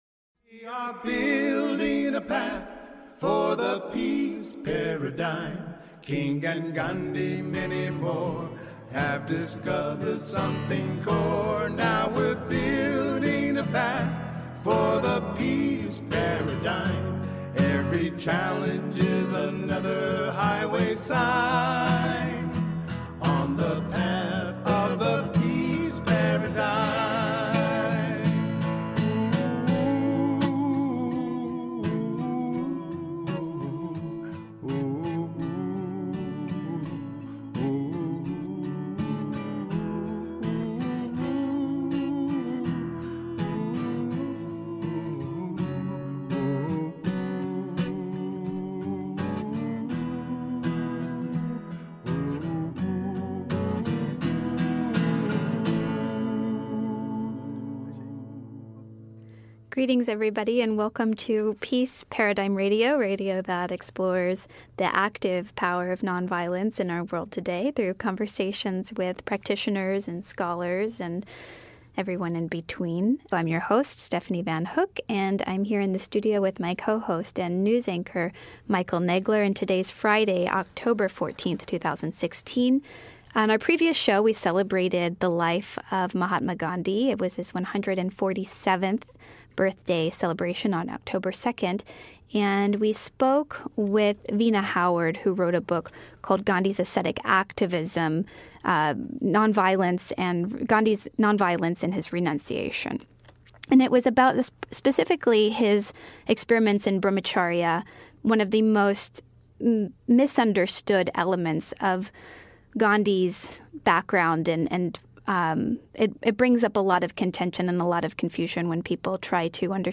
conduct a Q and A about Gandhi, his life, his motivations, and his work.